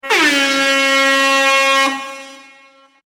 Airhorn Sound Button - Free Download & Play